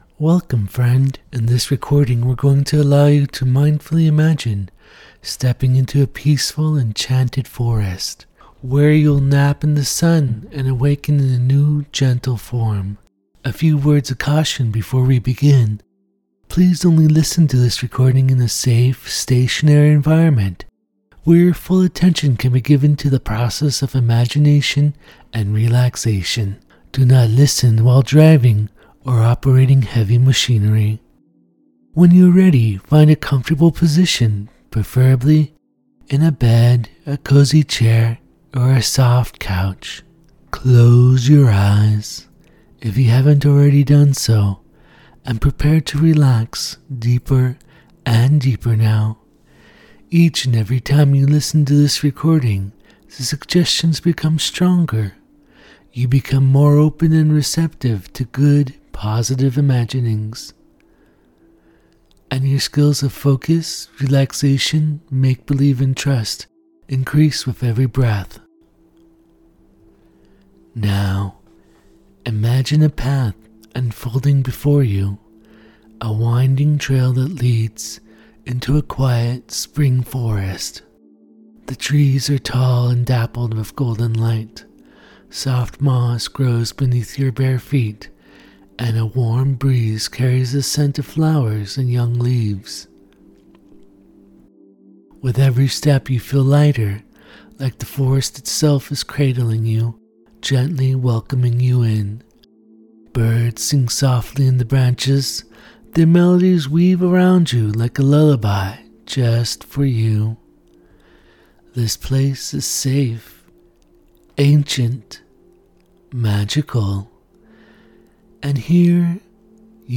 Step into the gentle embrace of springtime with this cozy, transformational guided imagery. In this soothing journey, you’ll gradually relax and awaken the spirit of a faun within — light, playful, and free among blooming meadows and sun-dappled woods.